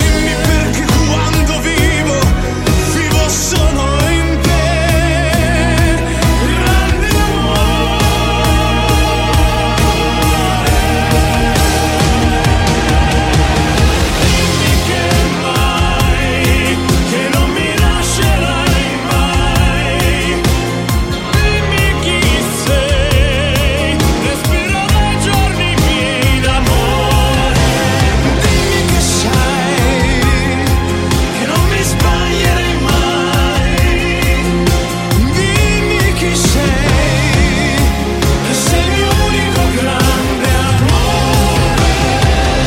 Genere: pop, dance